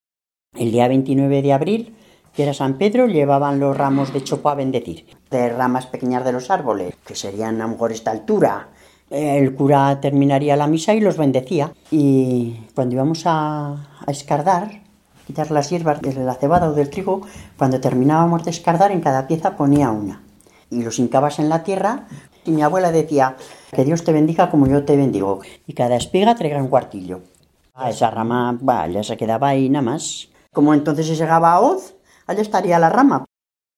Clasificación: Religiosidad popular, oraciones
Lugar y fecha de recogida: Manjarrés, 29 de mayo de 2007